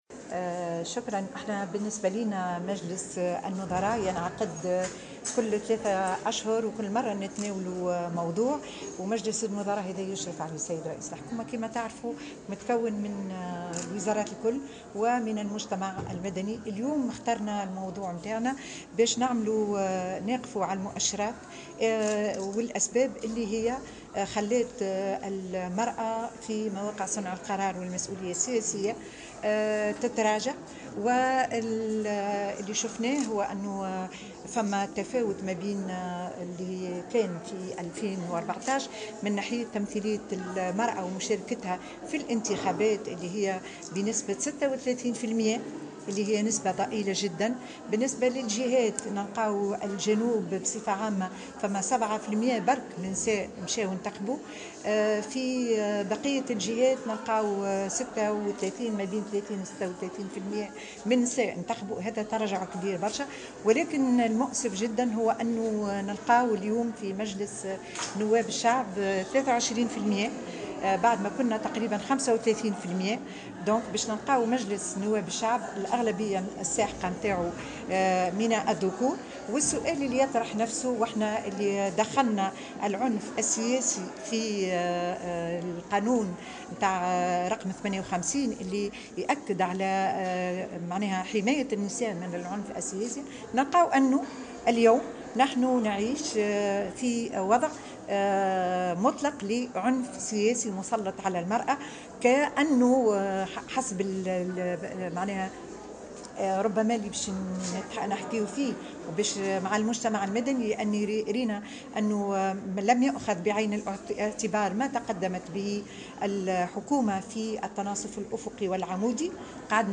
قالت وزيرة المرأة نزيهة العبيدي في تصريح لمراسلة الجوهرة "اف ام" اليوم الإثنين 4 نوفمبر 2019 إن مجلس النظراء المنعقد اليوم مخصص للوقوف على المؤشرات و الأسباب التي جعلت مكانة المرأة في مواقع صنع القرار السياسية تتراجع.